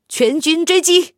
SU-122A夜战语音.OGG